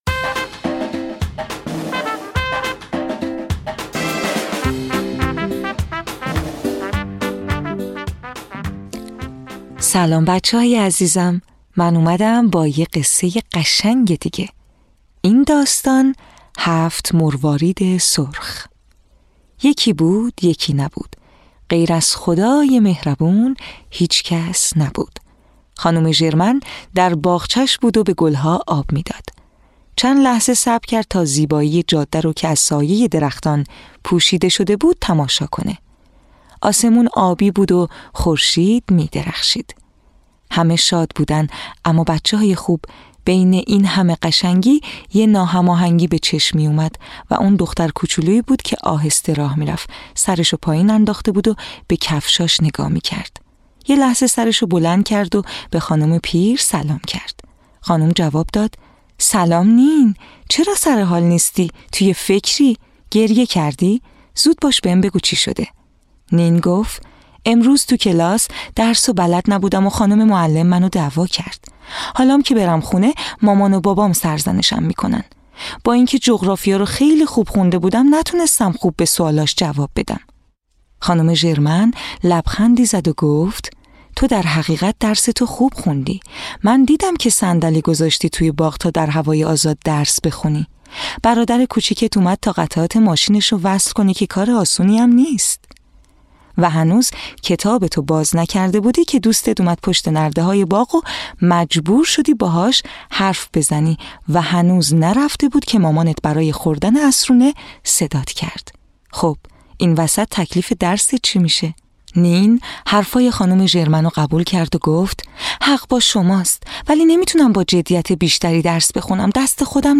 قصه های کودکانه صوتی – این داستان: هفت مروارید سرخ
تهیه شده در استودیو نت به نت